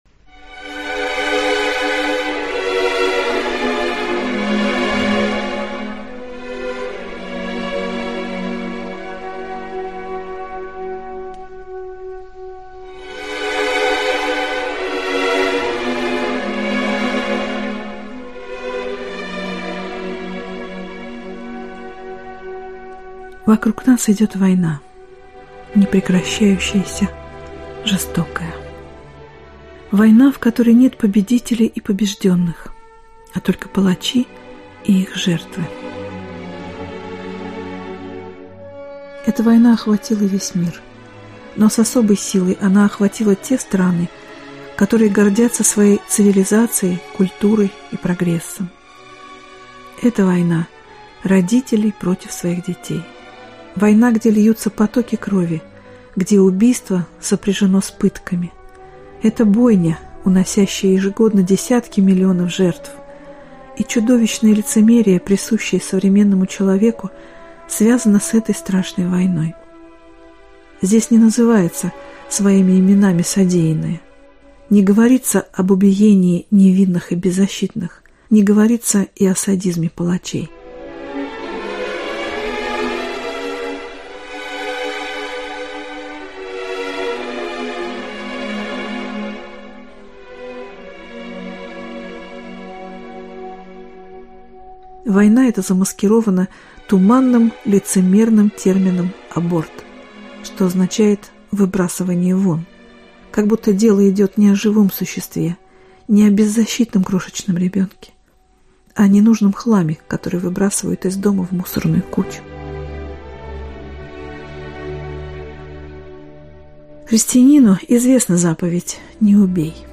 Аудиокнига Мама, помолись обо мне | Библиотека аудиокниг